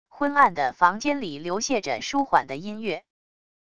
昏暗的房间里流泻着舒缓的音乐wav音频